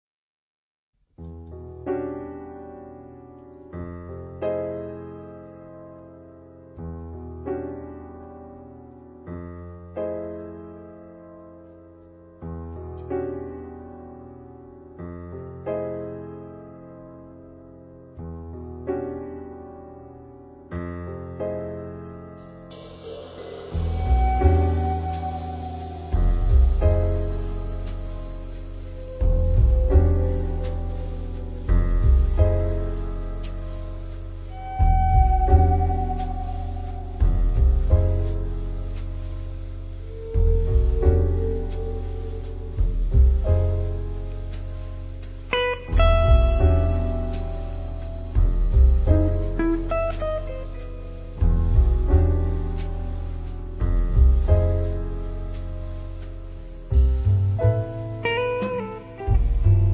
chitarra
pianoforte
contrabbasso
batteria
Il suono del gruppo risulta compatto